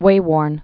(wāwôrn)